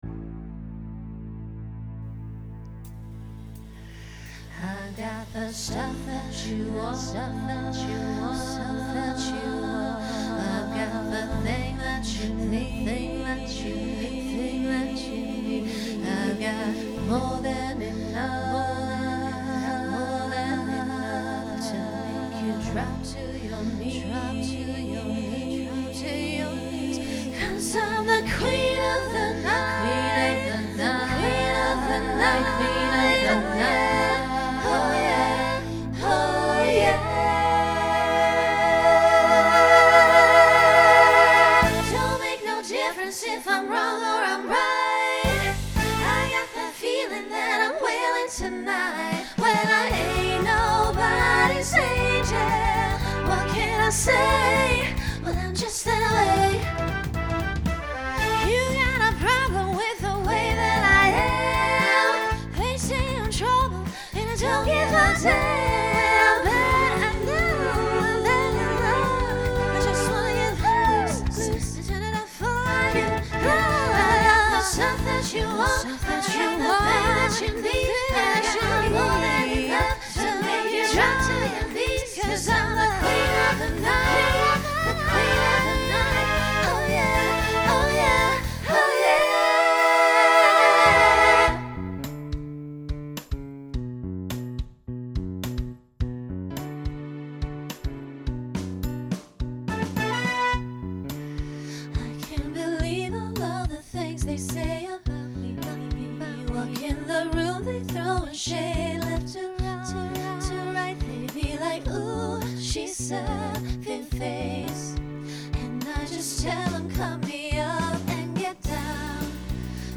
Begins with a trio to facilitate costume change.
Genre Rock Instrumental combo
Voicing SSA